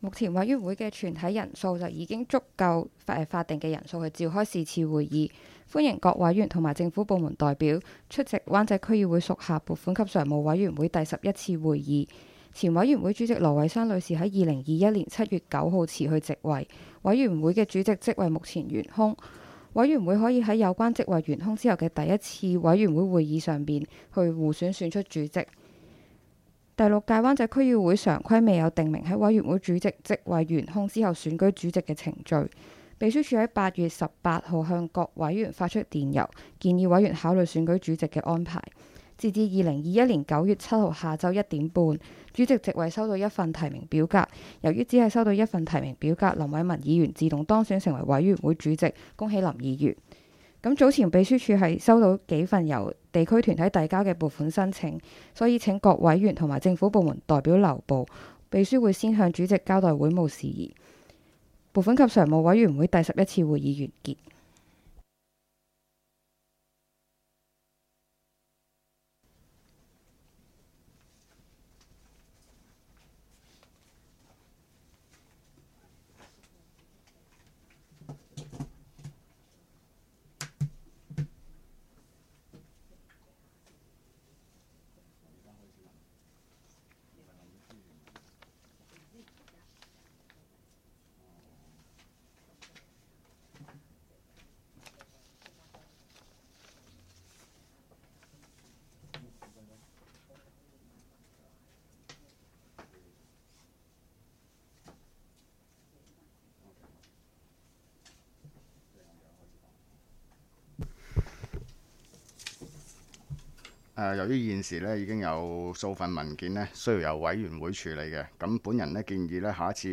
委员会会议的录音记录
拨款及常务委员会第十一次会议 日期: 2021-09-07 (星期二) 时间: 下午2时30分 地点: 香港轩尼诗道130号修顿中心21楼 湾仔民政事务处区议会会议室 议程 讨论时间 1 选举拨款及常务委员会主席 00:02:20 全部展开 全部收回 议程:1 选举拨款及常务委员会主席 讨论时间: 00:02:20 前一页 返回页首 如欲参阅以上文件所载档案较大的附件或受版权保护的附件，请向 区议会秘书处 或有关版权持有人（按情况）查询。